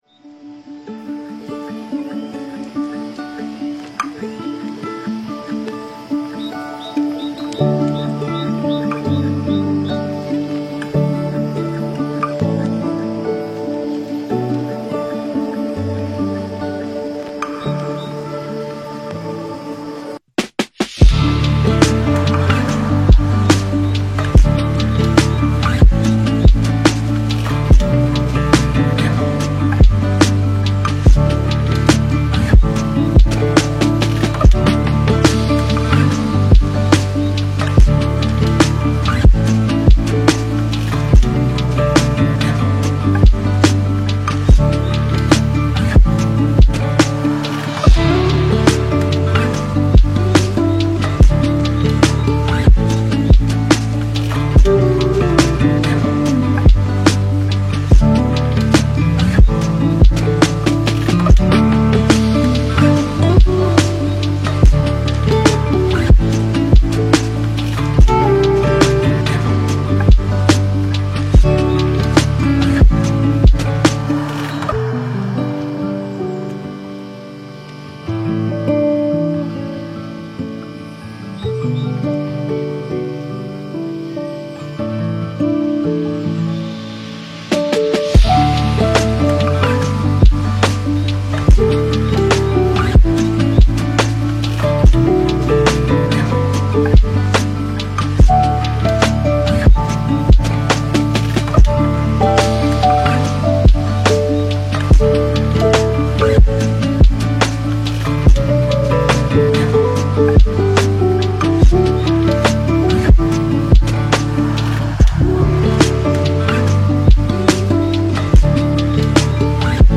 Мы подобрали для вас лучшие lo-fi песни без слов.
Весенняя мелодия 🌱